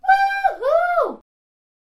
Scream of Joy